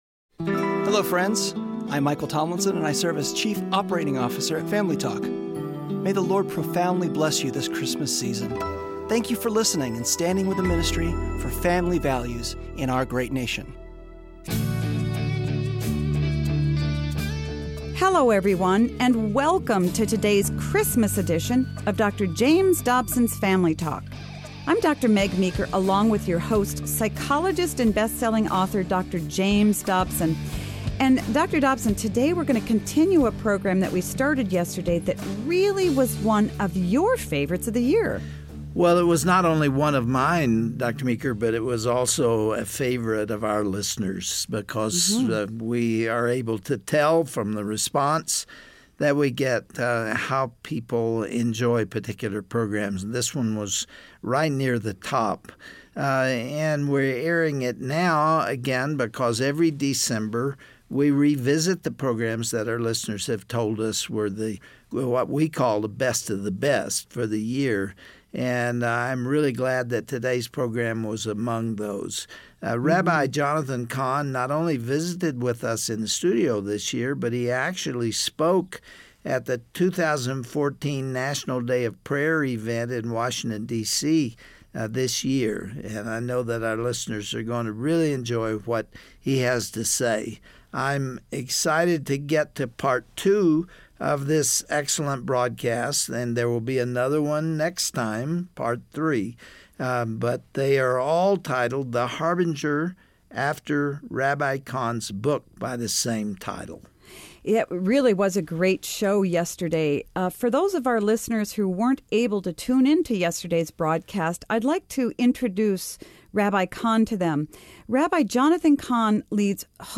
On the next edition of Family Talk, Dr. James Dobson will interview Rabbi Jonathan Cahn about the future of America.